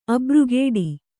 ♪ abrugēḍi